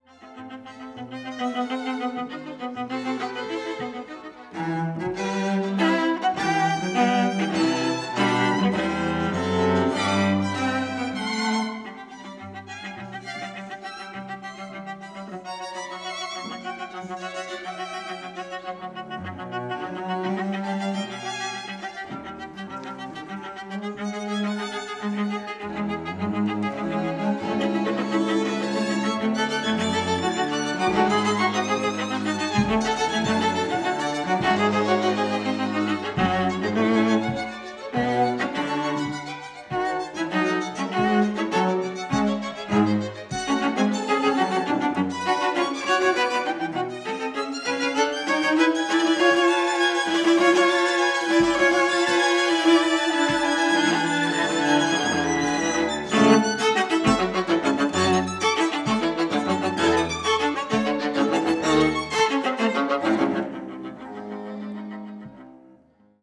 Tetrahedral Ambisonic Microphone
Recorded February 4, 2010, Jessen Auditorium, University of Texas at Austin. Quartet practicing for recording session.
Credits: Aeolus String Quartet.